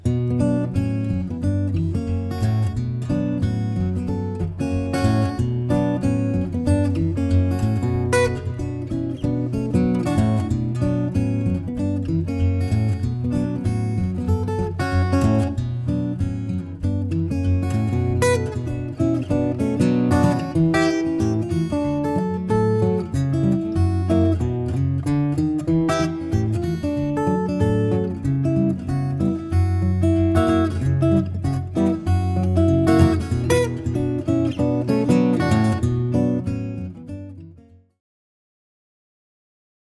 Played on Gibson J45.